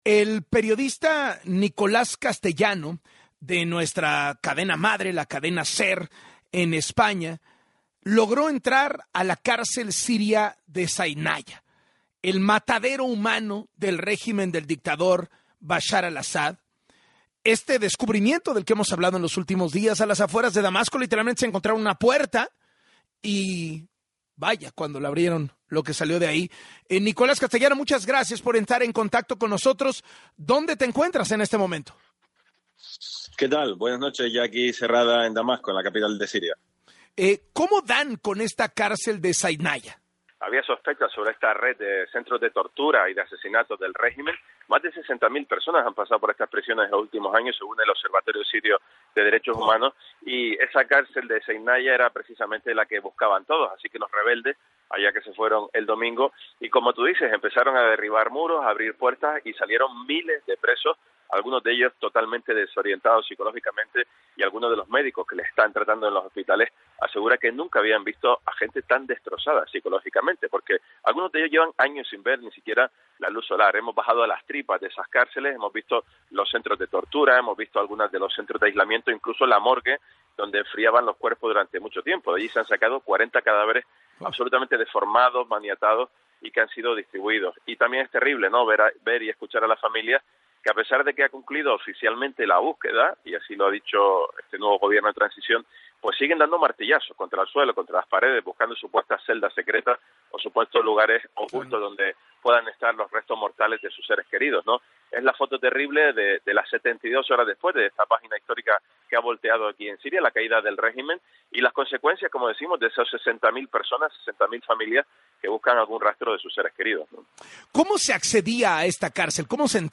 En entrevista con Carlos Loret de Mola